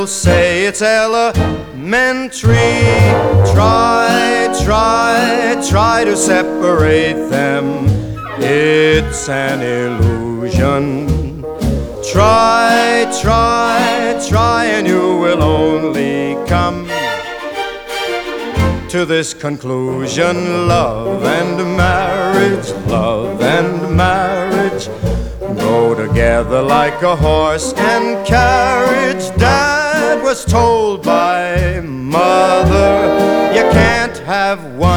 # Easy Listening